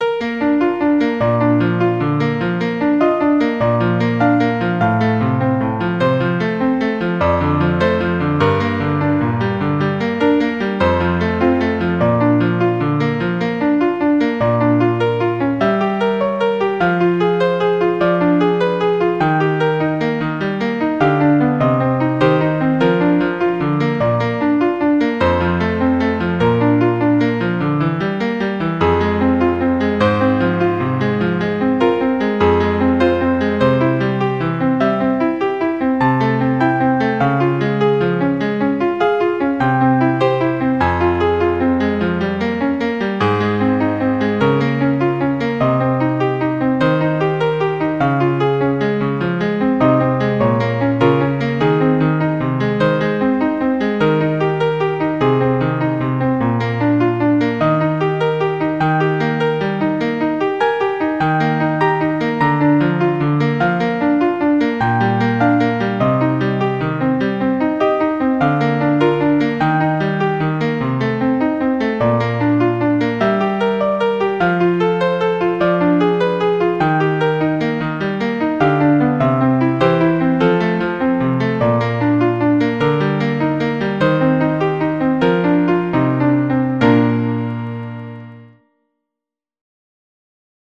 - Piano Music, Solo Keyboard - Young Composers Music Forum
(apprentice) Here is a variation on a motive by Weber (B flat major) with figuration in middle part, as an exercise (ch64, 4.6) from the book 'Material used in Musical Composition (1909)' by professor Percy Goetschius.